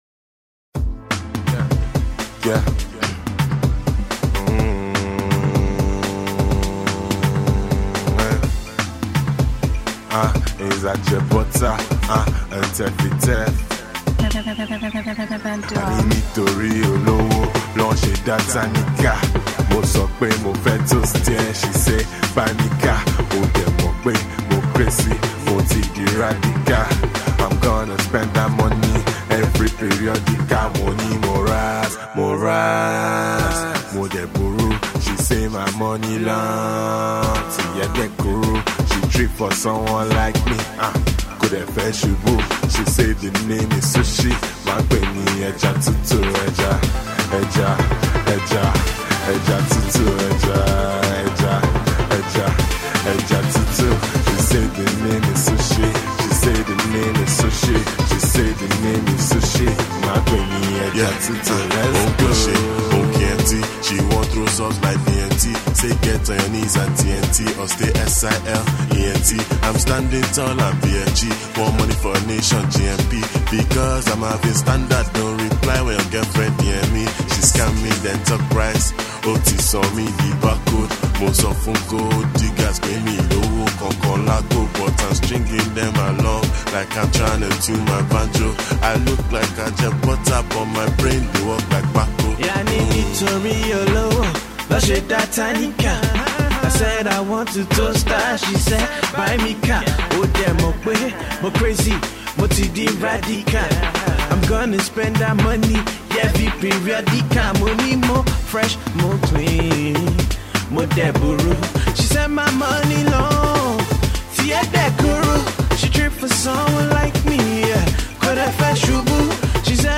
bumping
a rising crooner, teams up up with fellow UK-based rapper